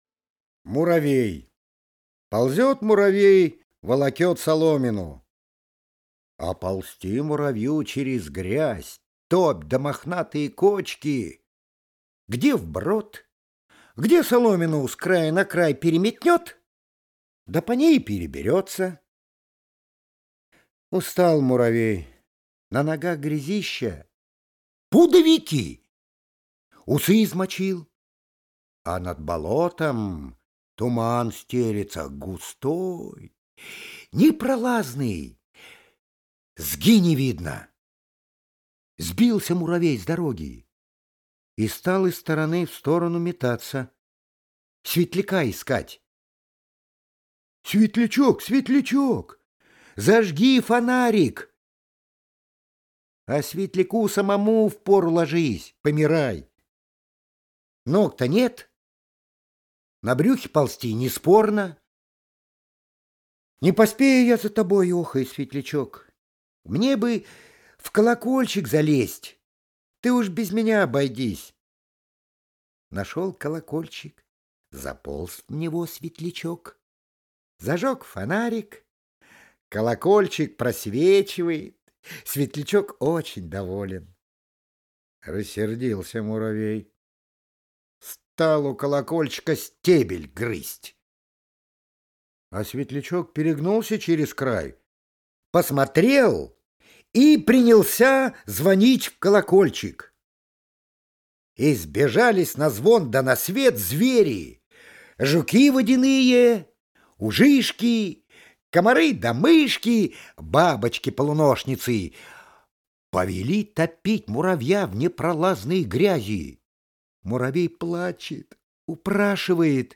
Муравей - аудиосказка Алексея Толстого - слушать онлайн | Мишкины книжки